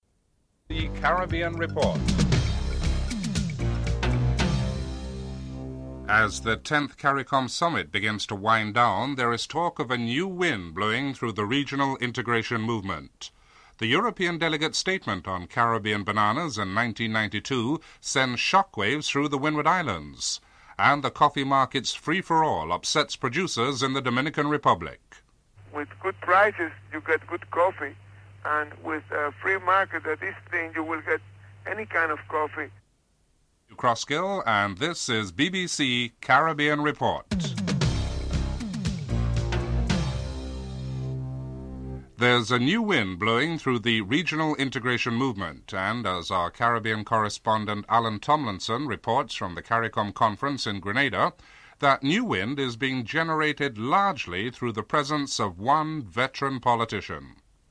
1. Headlines (00:13-00:52)
4. Financial news (06:21-07:49)